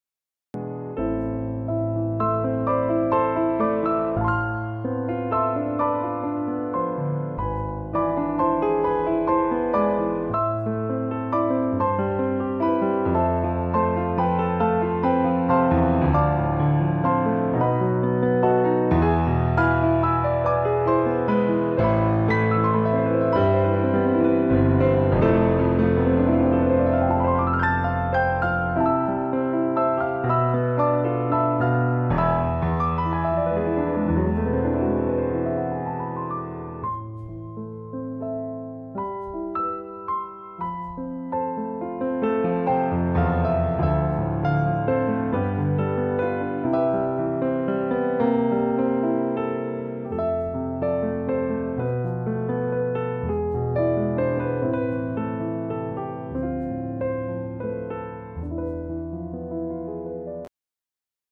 Noire CFX9大三角钢琴，是一款音色很纯正的雅马哈舞台大钢琴，音色优美质感丰富
Noire CFX9 雅马哈大三角钢琴 音色，文件大小：14GB，Kontakt标准音色库，兼容Windows/MAC
音色展示
钢琴